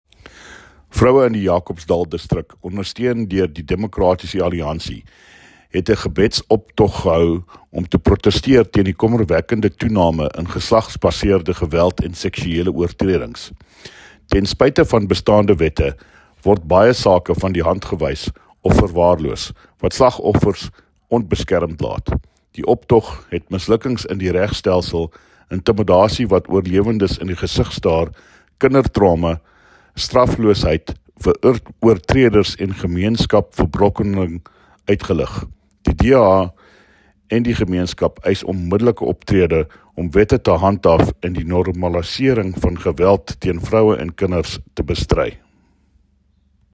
Afrikaans soundbites by David Mc Kay MPL  and Sesotho soundbite by Jafta Mokoena MPL with images here, here, and here